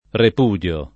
rep2dLo], ‑di (raro, alla lat., -dii): elette intelligenze, che repudiano l’errore comune [el$tte intelliJ$nZe, ke rrep2dLano l err1re kom2ne] (Cantù)